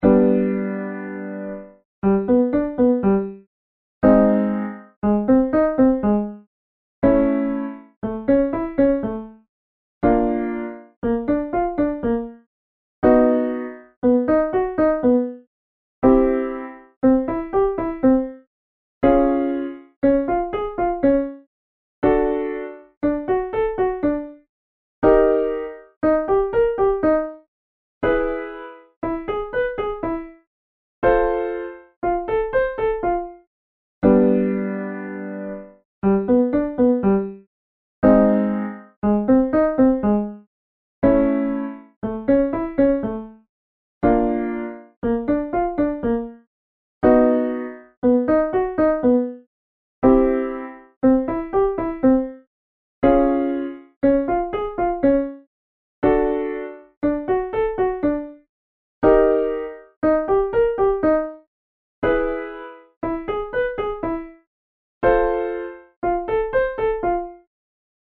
Triads
triads-online-program